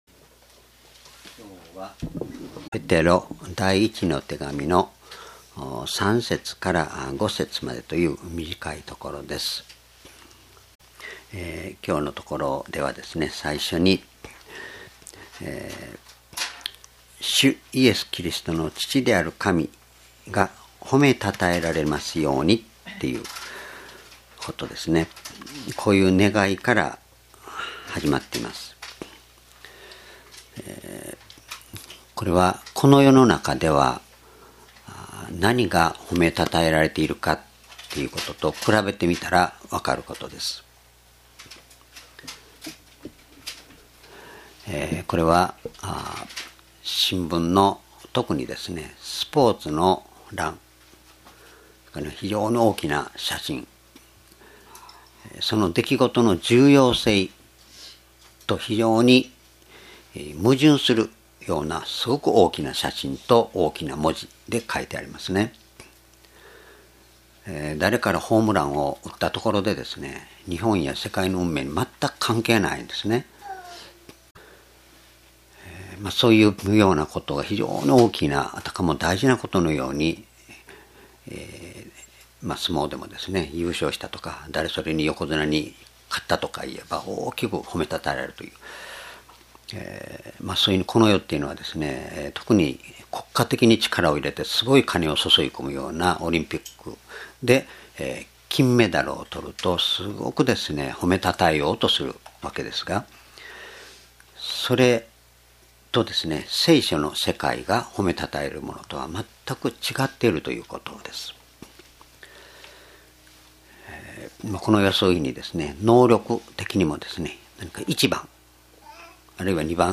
主日礼拝日時 ２０１５年２月２２日 聖書講話箇所 ペテロの手紙一 1章3-5 「新生と生きた希望」 ※視聴できない場合は をクリックしてください。